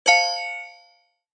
click_1.mp3